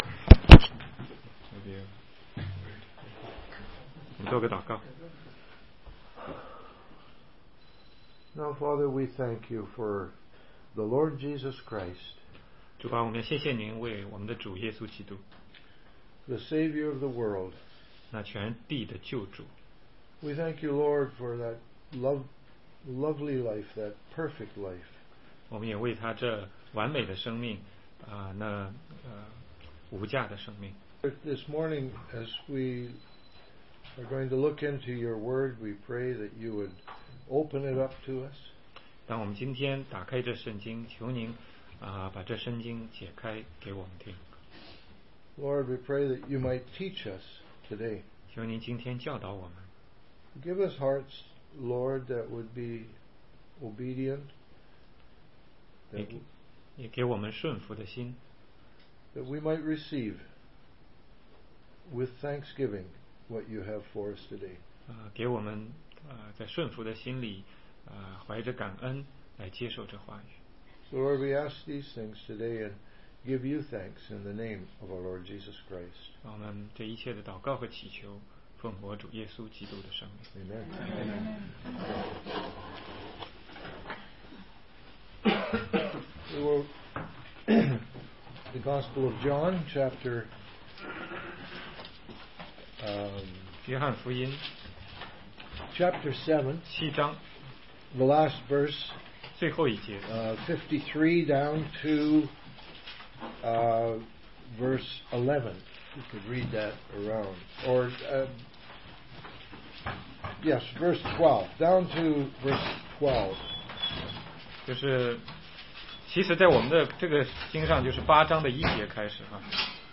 16街讲道录音 - 约翰福音8章1-12节